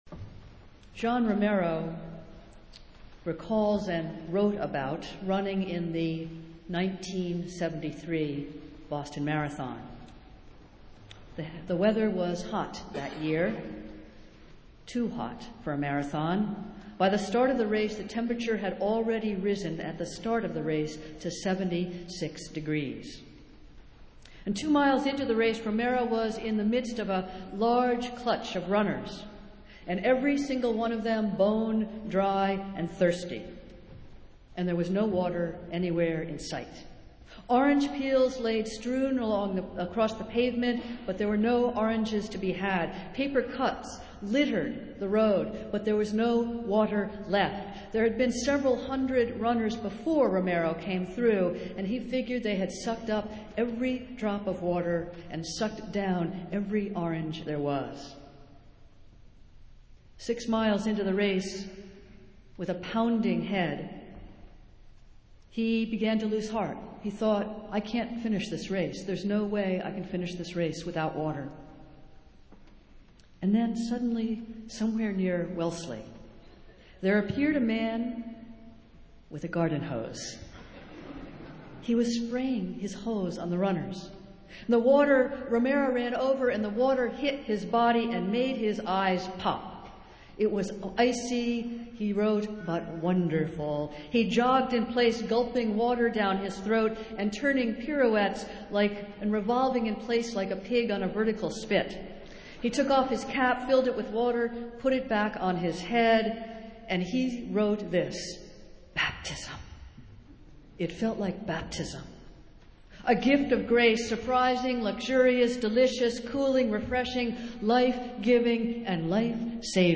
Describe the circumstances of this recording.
Festival Worship - Confirmation Sunday